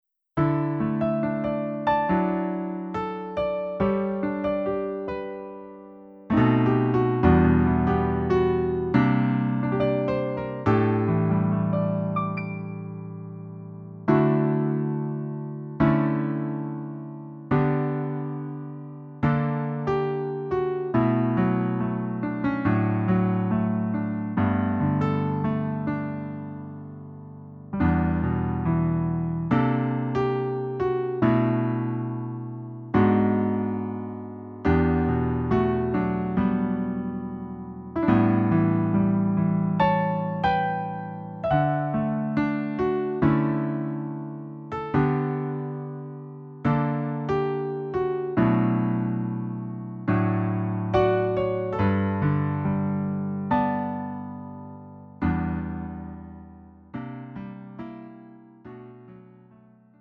음정 원키 4:09
장르 가요 구분 Lite MR